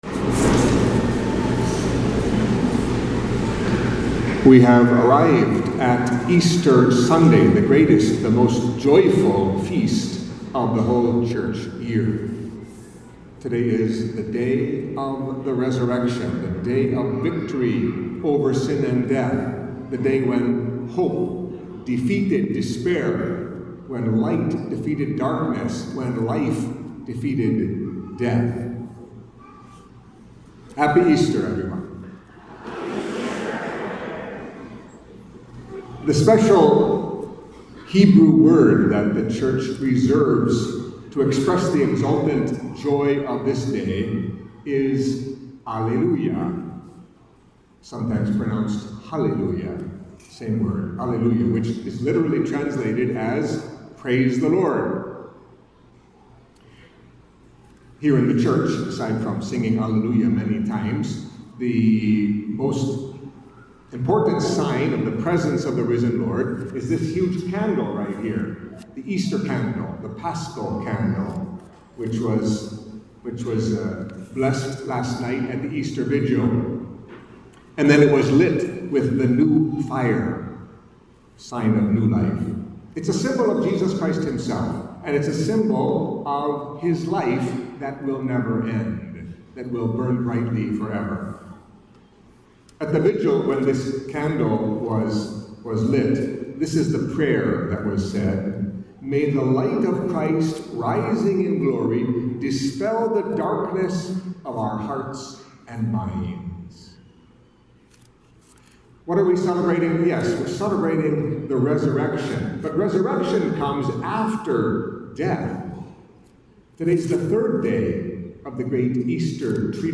Catholic Mass homily for Easter Sunday of the Resurrection of the Lord